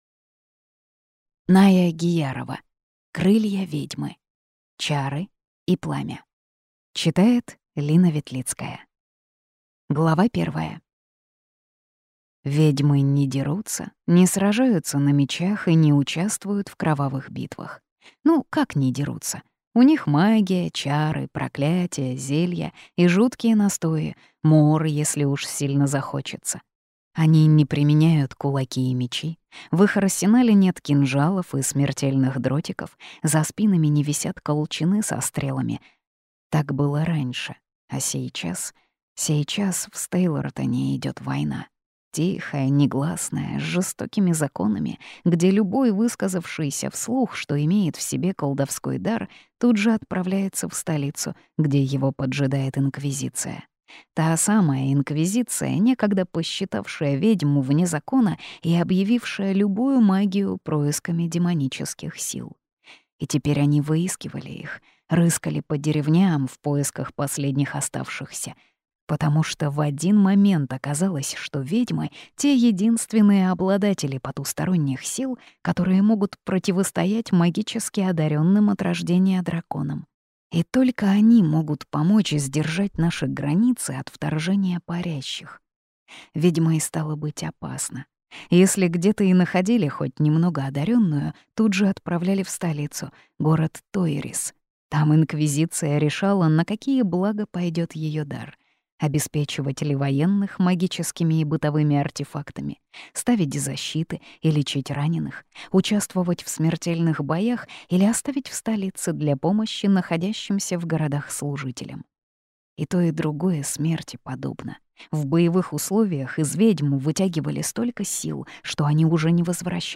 Слушать аудиокнигу Первозданная сила. Шаг к цели полностью